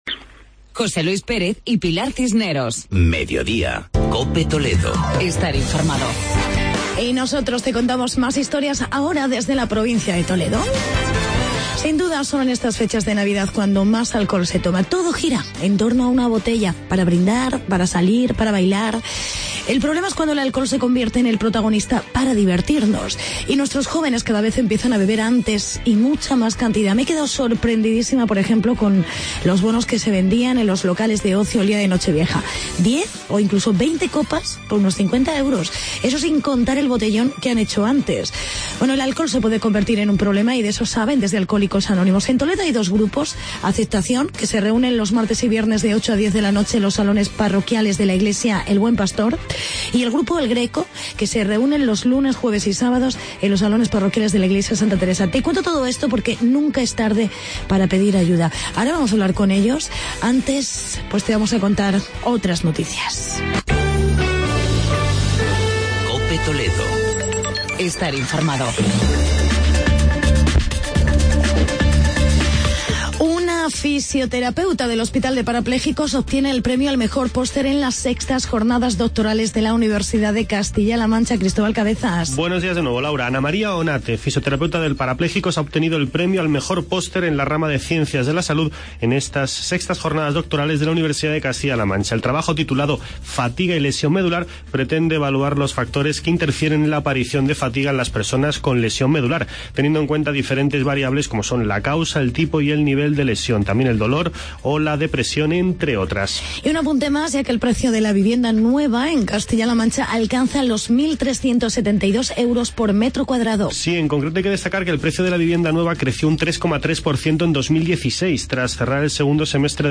Actualidad y entrevista con la Asociación de Alcohólicos Anónimos de Toledo.